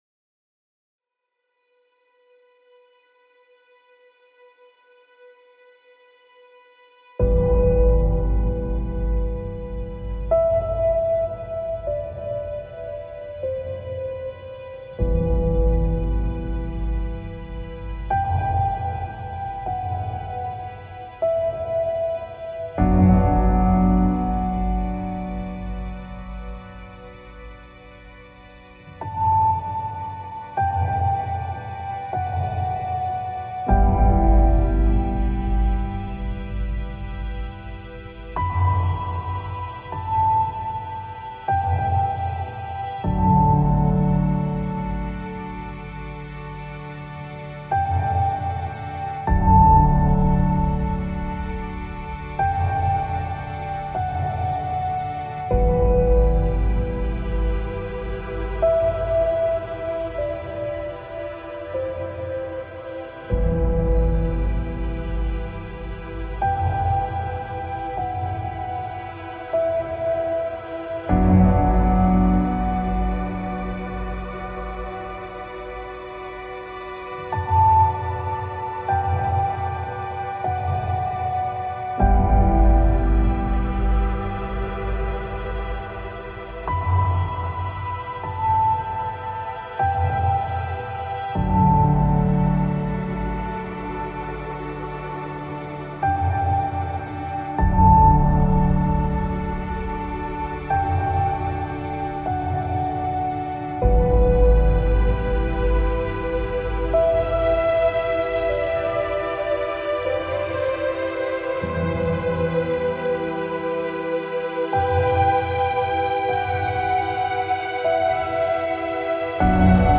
ابری و بارانی , پیانو , مدرن کلاسیک , موسیقی بی کلام